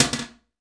Toilet Lid Close Sound
household
Toilet Lid Close